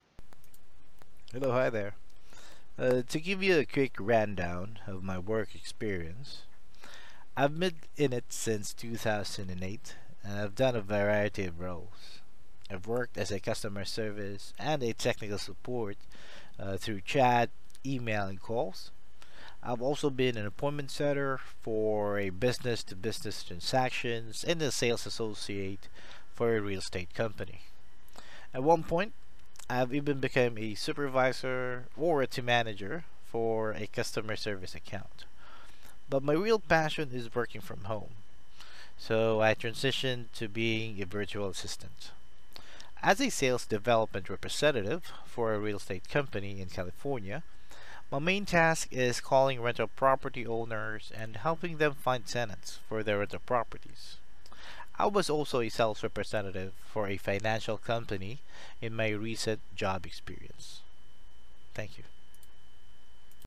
Self Introduction